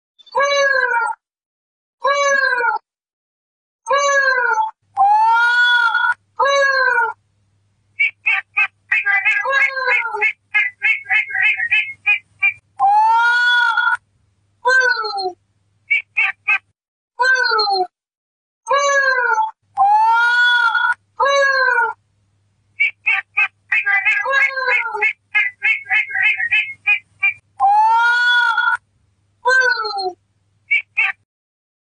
黄腹角雉叫声